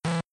error.wav